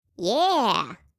알림음 8_HobbitYeah2.mp3